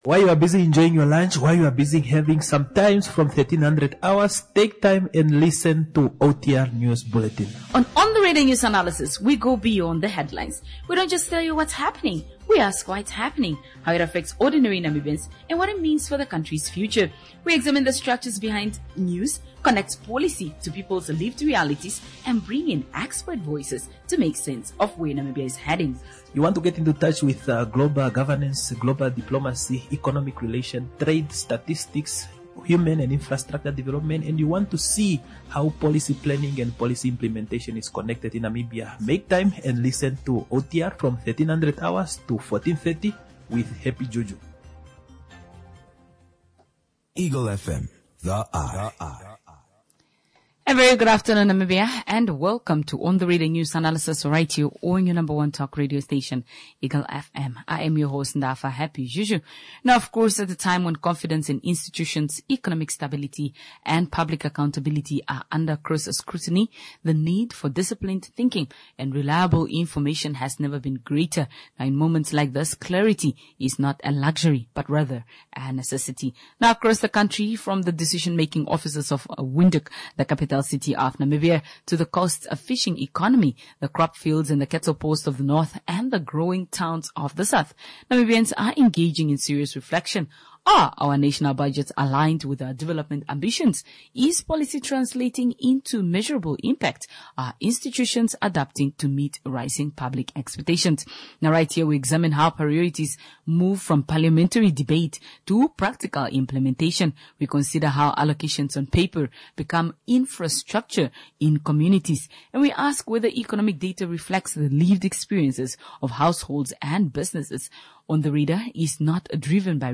what it is & what it means The Ministry of Information and Communication Technology recently held stakeholder consultations on the draft of the Cybercrime bill. A discussion on cybercrime bill